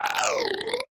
Minecraft Version Minecraft Version 25w18a Latest Release | Latest Snapshot 25w18a / assets / minecraft / sounds / mob / strider / death4.ogg Compare With Compare With Latest Release | Latest Snapshot
death4.ogg